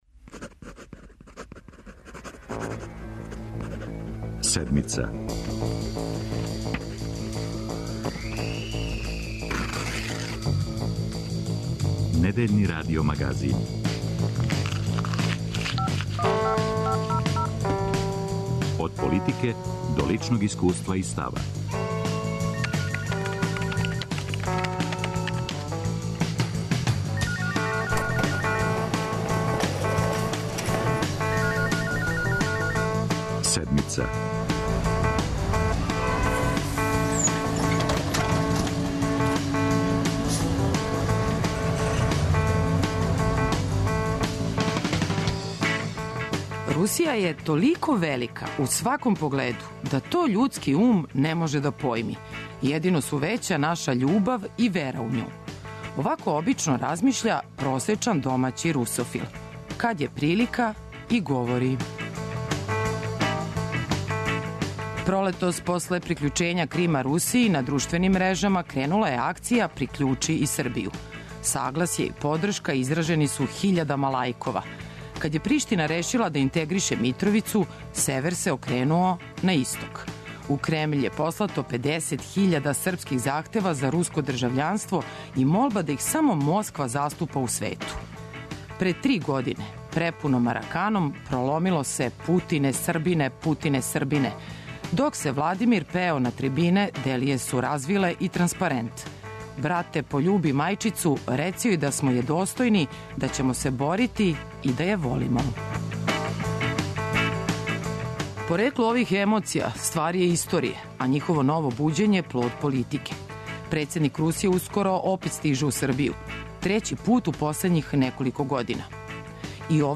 О поруци коју носи Владимир Путин, неутралној политици Београда, економској сарадњи, нерешеним енергетским питањима и сукобу са колегом Кирбијем, за Седмицу говори амбасадор Руске федерације Александар Чепурин.